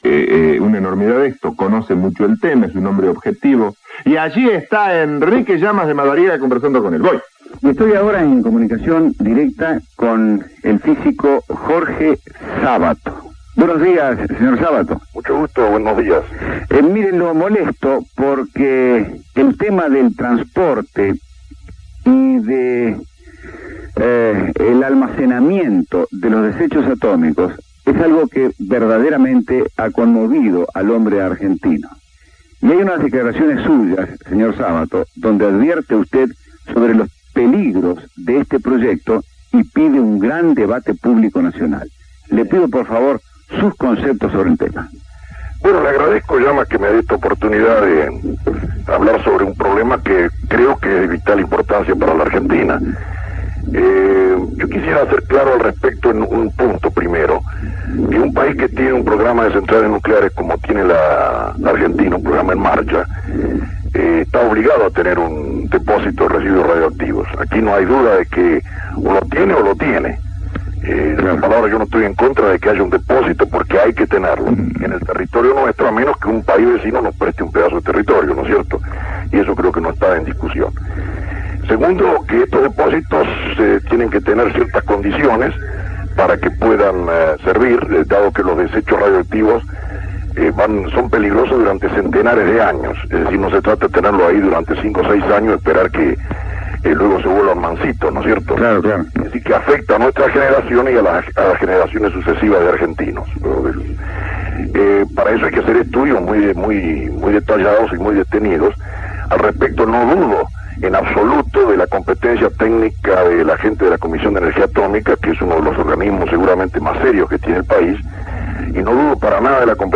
Enrique Llamas de Madariaga periodista y locutor argentino, entrevista a Jorge. A. Sabato en Radio Continental.